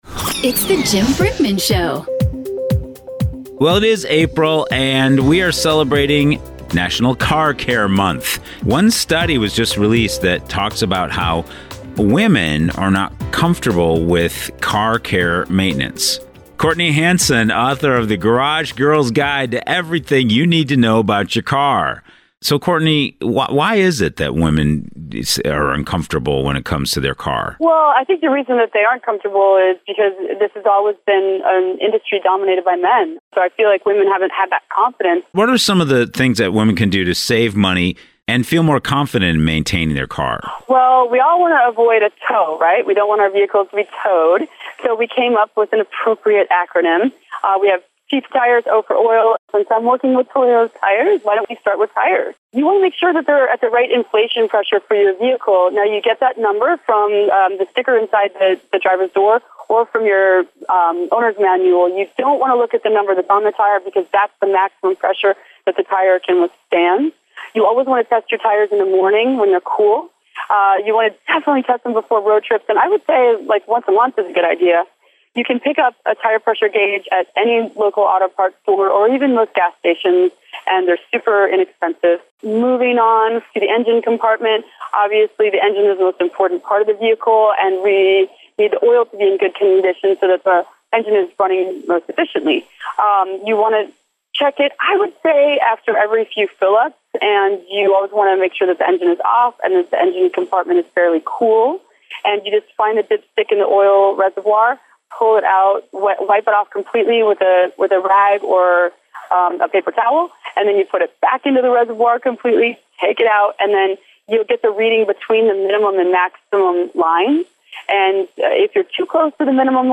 One study was just released that women aren’t comfortable with car care maintenance.  Courtney Hansen is with us to bring some easy to remember tips to care for your car and feel more comfortable in maintaining your vehicle.